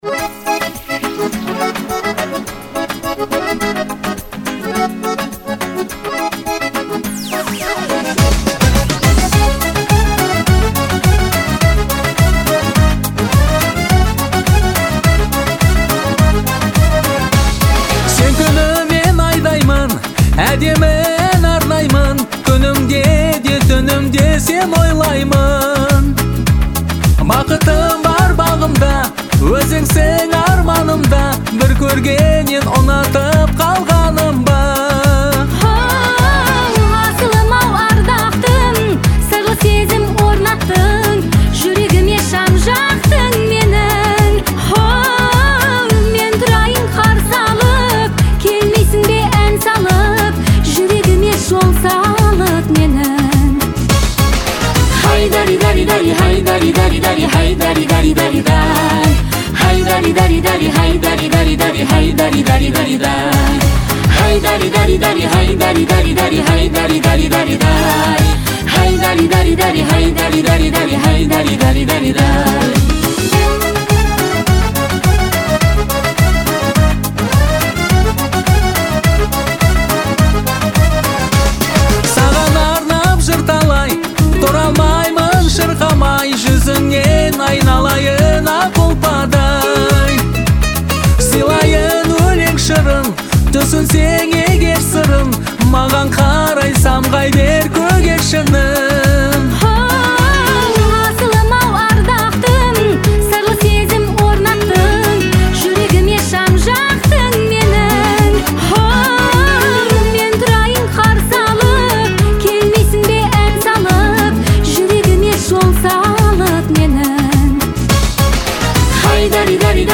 Категория: Казахские песни